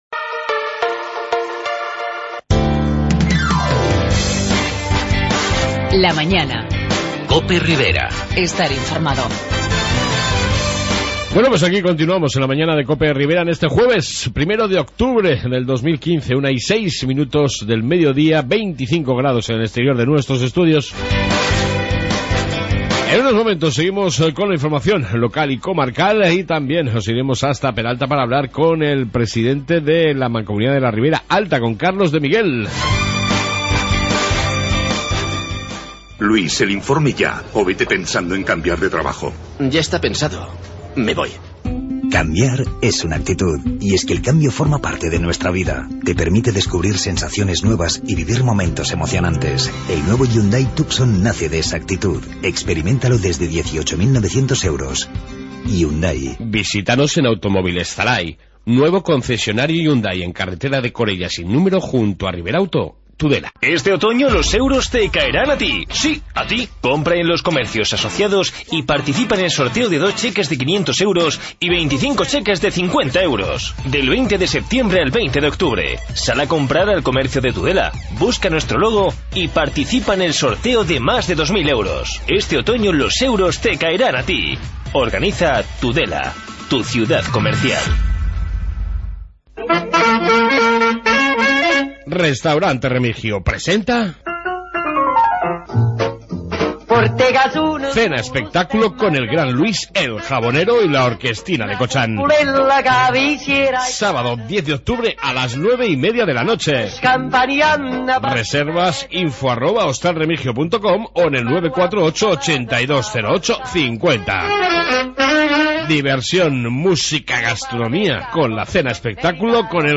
AUDIO: Informe Policía municipal, noticias Riberas y Entrevista con el reelegido Presidente de la Mancomunidad de la Ribera alta, Carlos de Miguel.